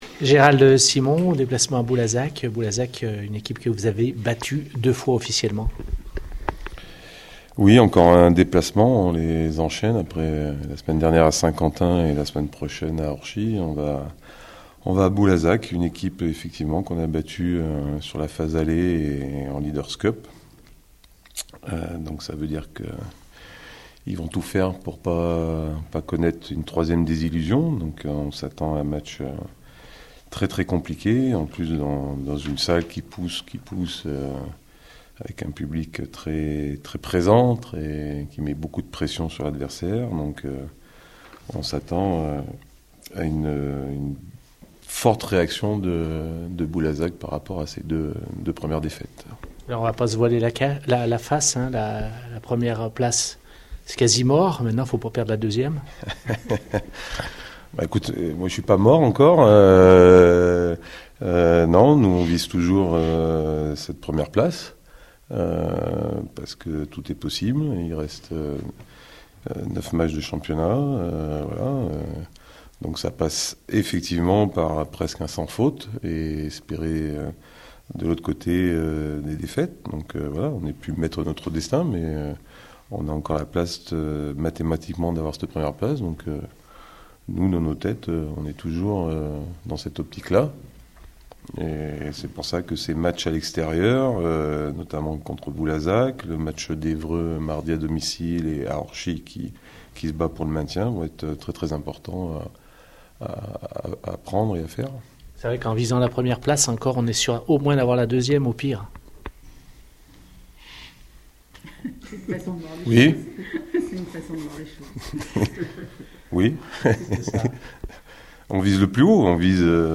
Ce jeudi 7 avril, la traditionnelle conférence de presse d’avant match a eu lieu.